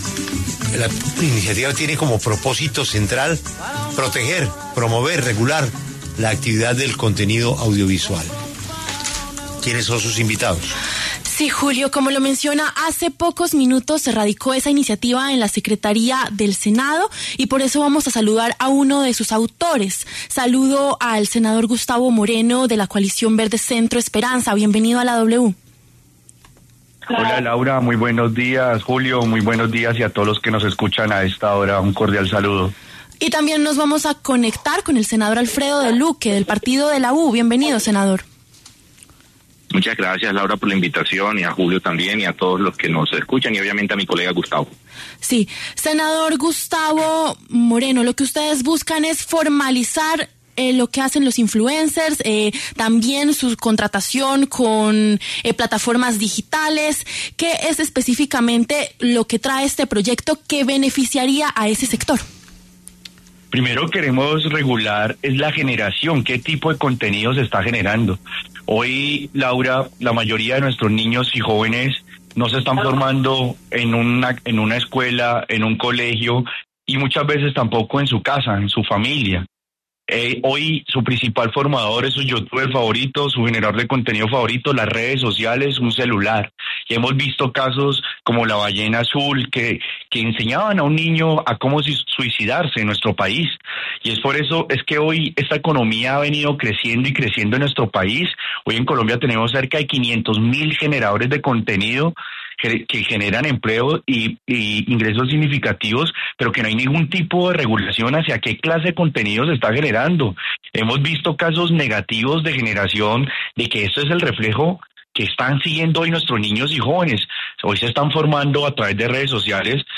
En La W hablaron los senadores Gustavo Moreno, autor del proyecto, y Alfredo Deluque, del Partido de la U.